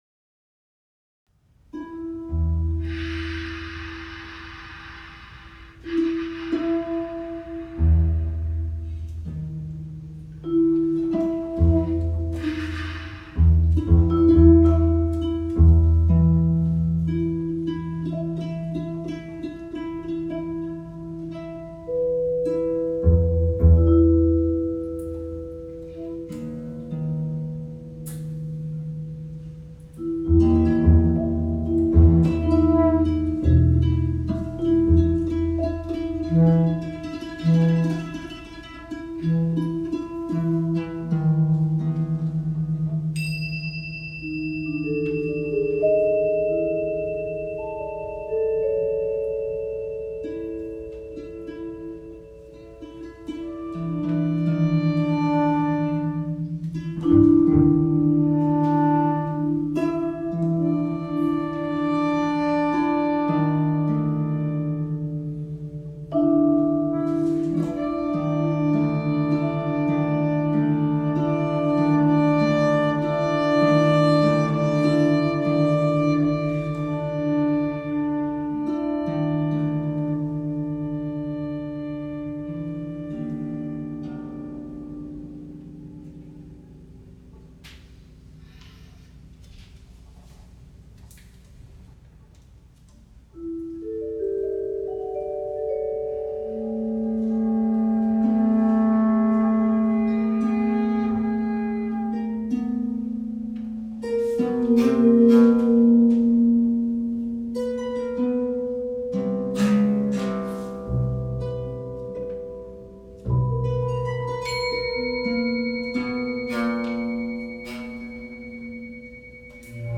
Composition for Double Bass, Bass Clarinet, Harp, and Percussion.
reverie is a take on a “fever dream,” an eerie and illusory meandering of the mind.
The majority of this piece is written using quiet sounds (the dream), contrasted by a sudden outpouring of loud sounds at the very end (like a jolt of consciousness ending the dream). A melody emerges in fragments over the course of the piece, offering the listener a guiding light in an otherwise bleak soundscape.